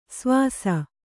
♪ svāsa